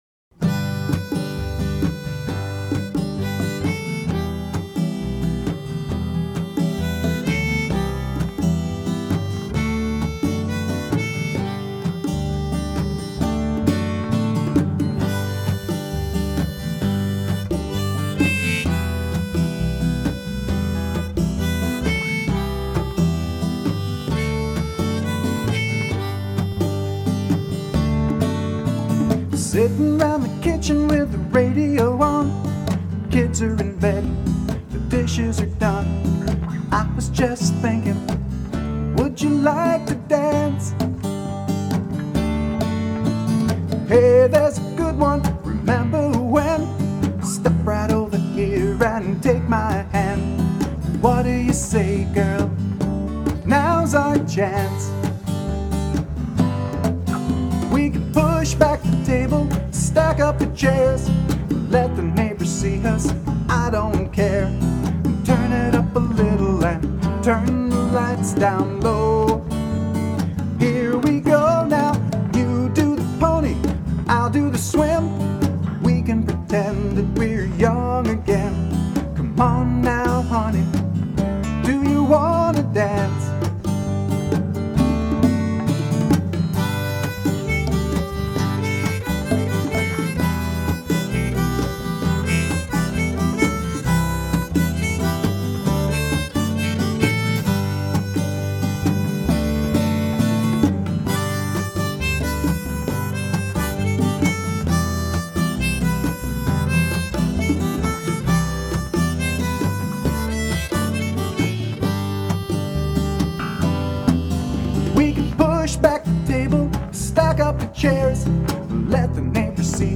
Such a simple song, but it brings up such vivid images that aren’t even part of the lyrics. The song has a familiar quality to it that makes me feel like I knew it before I heard it.